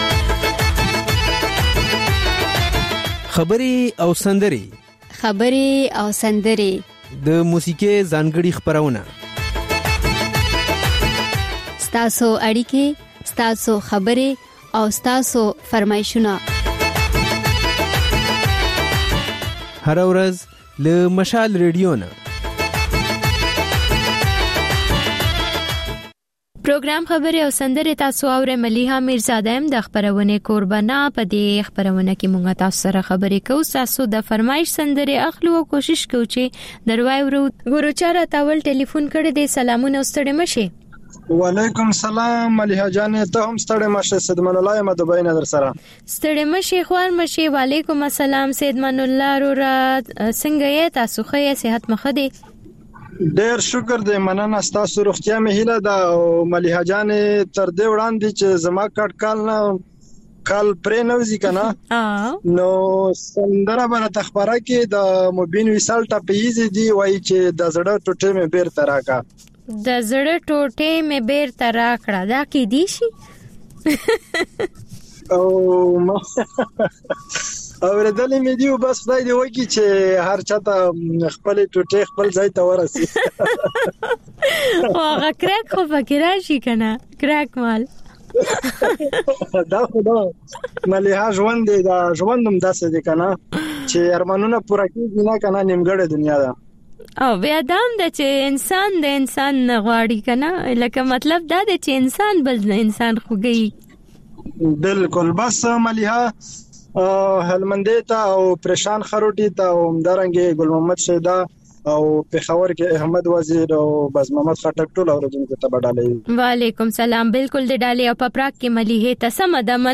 دلته د خبرې او سندرې خپرونې تکرار اورئ. په دې خپرونه کې له اورېدونکو سره خبرې کېږي، د هغوی پیغامونه خپرېږي او د هغوی د سندرو فرمایشونه پوره کېږي.